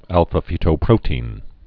(ălfə-fētō-prōtēn, -tē-ĭn)